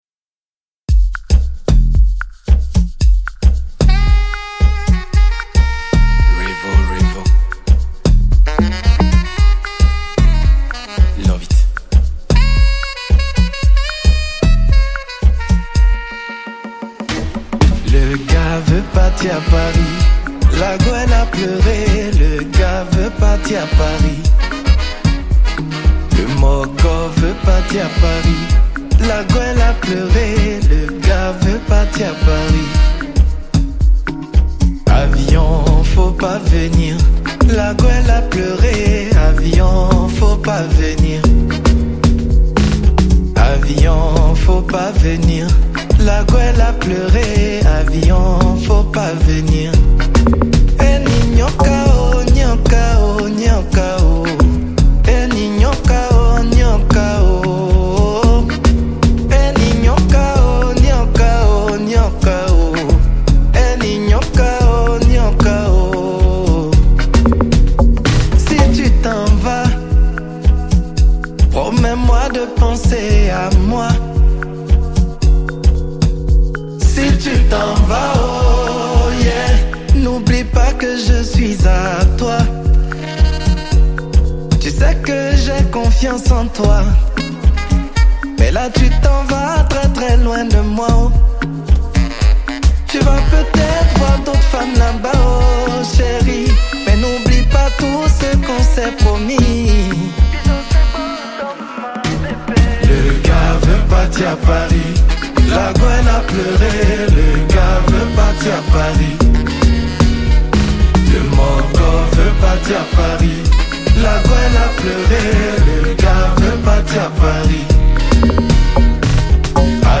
| Zouglou